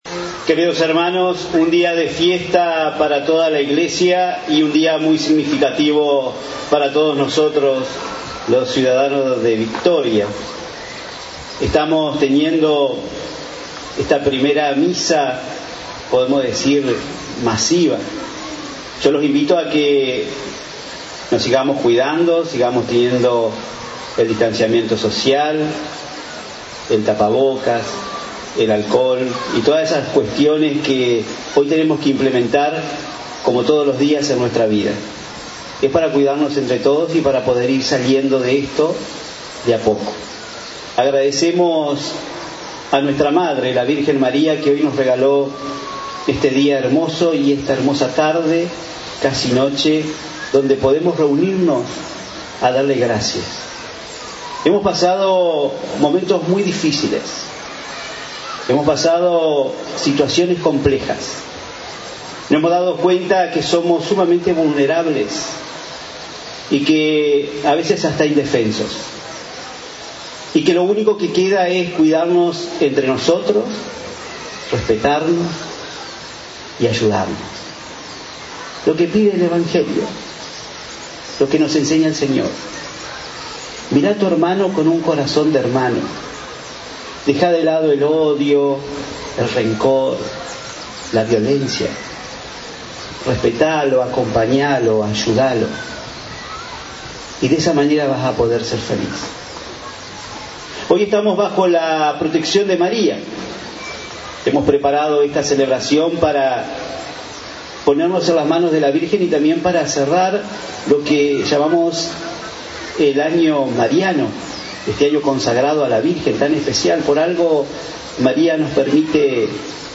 homilia.mp3